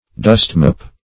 dustmop \dust"mop\ (d[u^]st"m[o^]p), n. a dry mop for dusting floors.